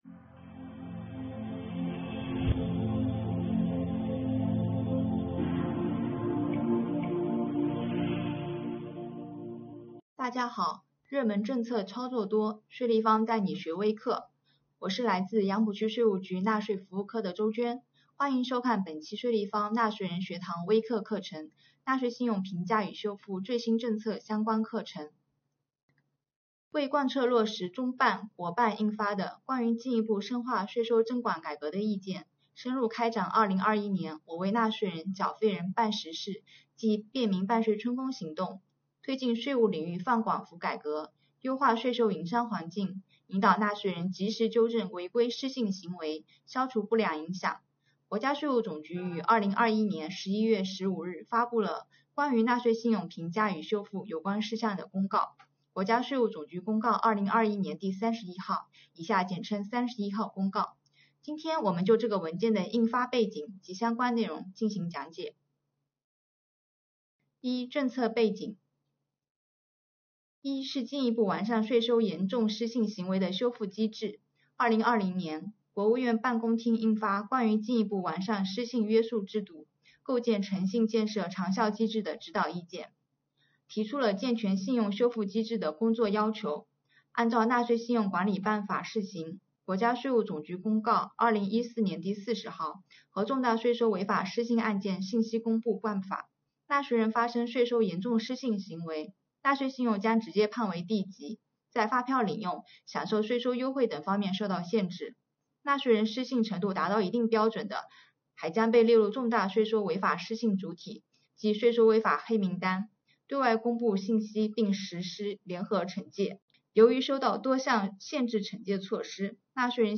这里有专业讲师为您授课，